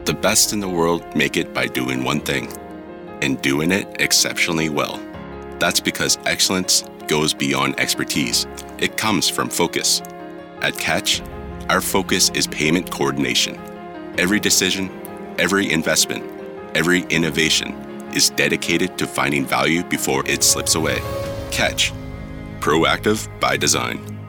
Deep authoritative male voice
Calm Informative Strong Deep